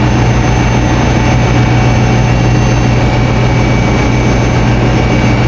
ec_rotor_in.wav